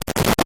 Звуки глитч-эффектов
Короткий звук с глитчем